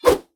CosmicRageSounds / ogg / general / combat / weapons / default_swingable / fire2.ogg
fire2.ogg